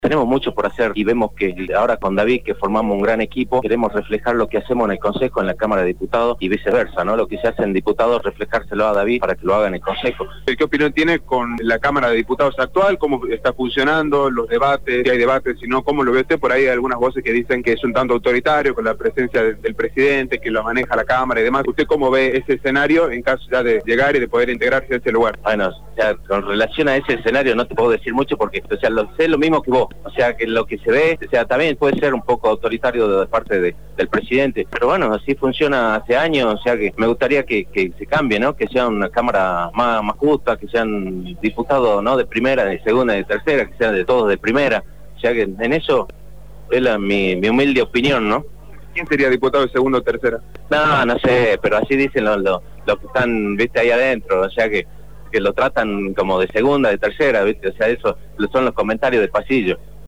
El concejal Mario Moreno, precandidato a diputado provincial por la lista “Memoria y Movilización”, dentro del «Frente Unidad y Renovación», se refirió al funcionamiento de la Cámara de Diputados.
“Tenemos mucho por hacer y vemos ahora con David (Leiva), que formamos un gran equipo, y queremos reflejar lo que hacemos en el Concejo en la Cámara de Diputados y viceversa, lo que se hace en Diputados reflejárselo a David para que lo haga en el Concejo”, manifestó Moreno a Radio Dinamo.